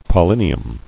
[pol·lin·i·um]